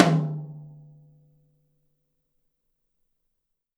H_TOM.WAV